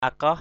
/a-kɔh/ (cv.) hakaoh h_k<H (t.) cạn, nông = peu profond. shallow. kraong ni aia akaoh min _k” n} a`% a_k<H m{N sông này nước cạn thôi = this river is just...